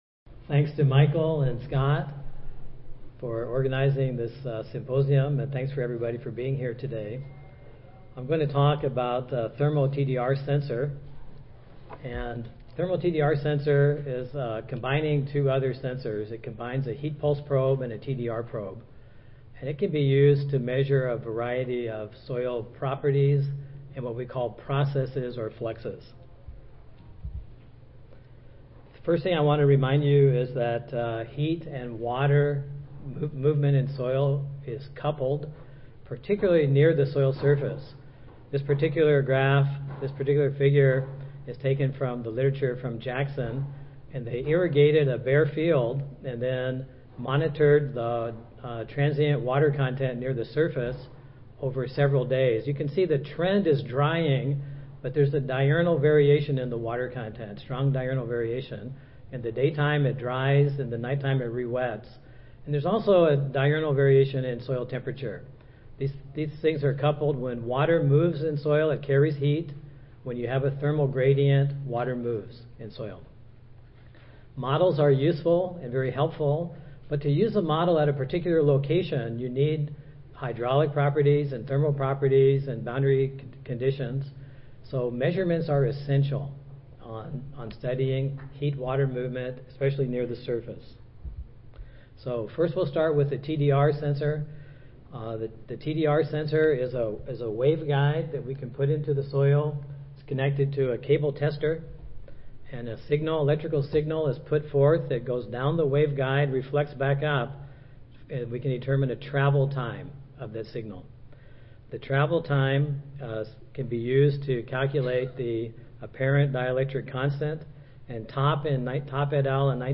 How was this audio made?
Session: Symposium--Recent Advances in Soil Physics Instrumentation and Sensors (ASA, CSSA and SSSA International Annual Meetings)